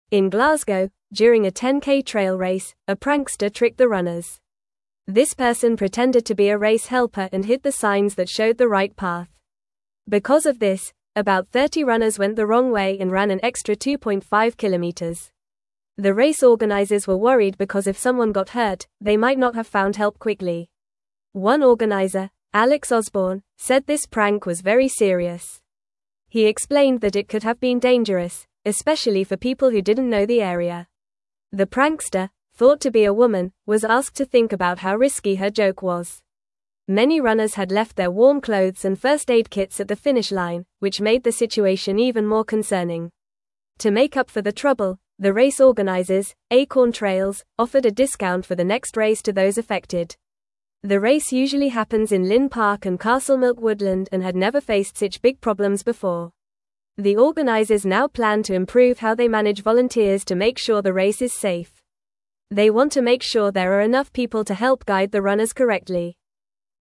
Fast
English-Newsroom-Lower-Intermediate-FAST-Reading-Trickster-Confuses-Runners-in-Glasgow-Race.mp3